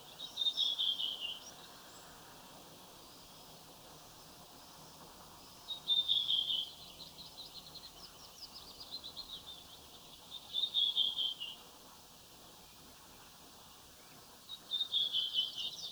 Kuulostaa punakylkirastaalta.
Se on kuitenkin tunnettavissa samanlaisena toistuvista, yksinkertaisista säkeistä, joita seuraa vaimeaa kitisevää viserrystä (kuuluu vain lähelle).
Lauluääni oli luonnossa todella voimakas, voimakkaampi kuin satakielellä tai mustarastaalla! Äänitin tuon äänitteen kännykällä viime kesänä.